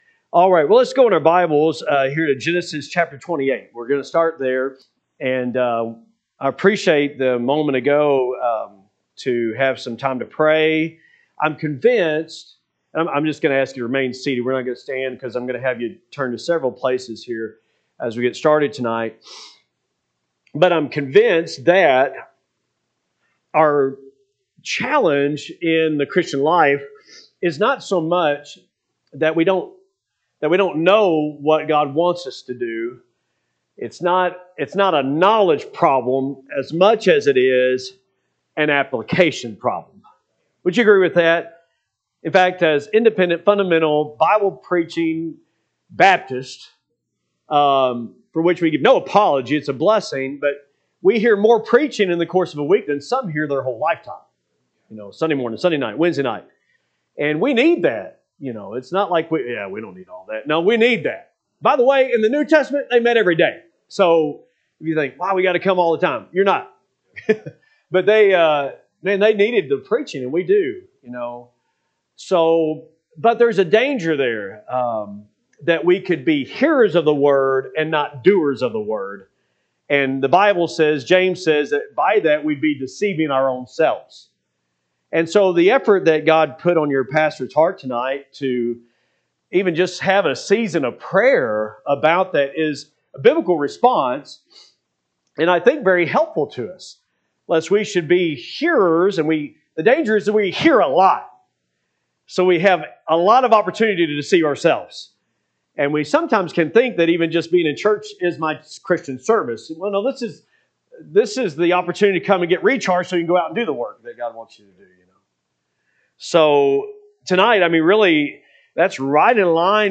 Spring Revival , Wednesday Evening